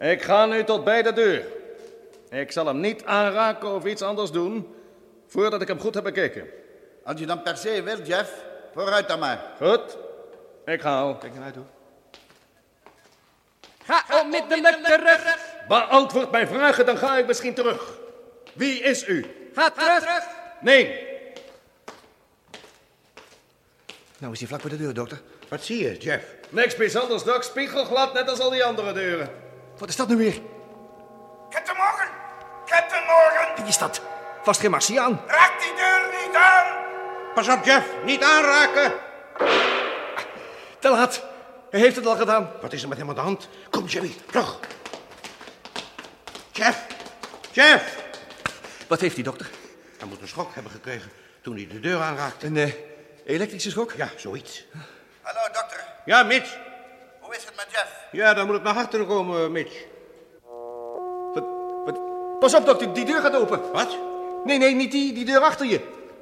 Wanneer Jet/Jeff naar de deur gaat, volgt er een klap.